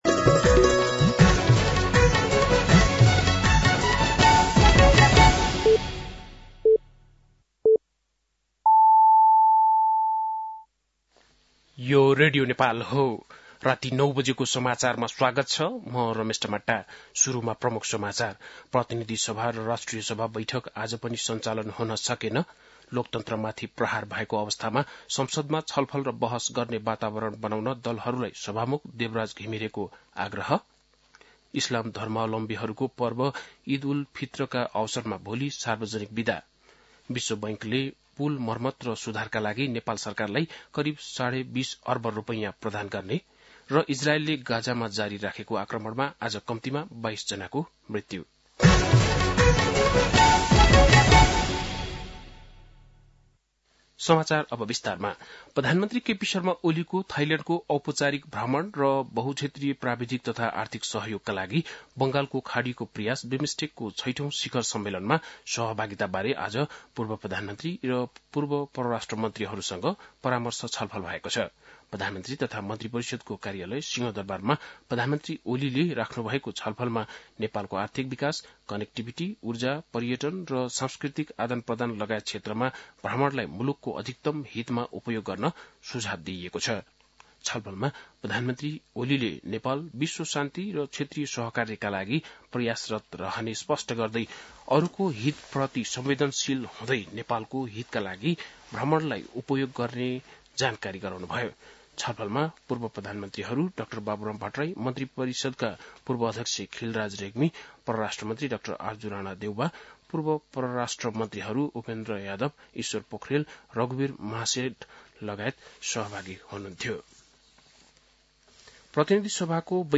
बेलुकी ९ बजेको नेपाली समाचार : १७ चैत , २०८१
9-pm-nepali-news-12-17.mp3